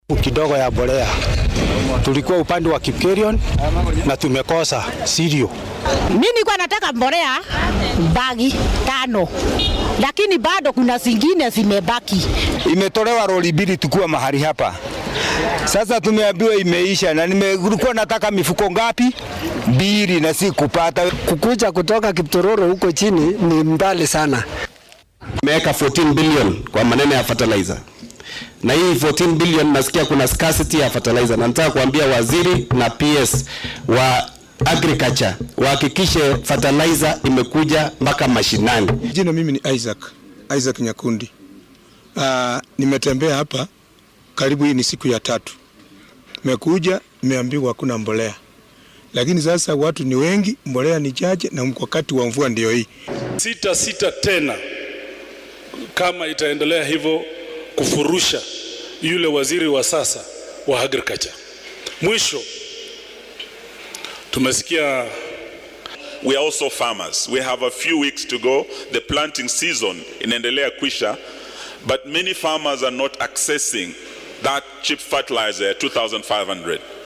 Beeralayda dalka ayaa ka cabanayaa Bacrimin la’aan, iyadoo qaarkood ay u safraan meelo kala duwan si ay u soo helaan badeecadan oo gabaabsi noqotay xilliyada beeraha. hogaamiyayaasha mucaaradka ayaa dalbanayaa in wasiirka beeraha Mutahi Kagwe uu arinta xaliyo hadii kale ay bilaabi doonaan habka xilka looga qaadayo. Qaar ka mid ah beeralayda oo arrintani ka cabanayaa ayaa hadaladooda waxaa ka mid ah.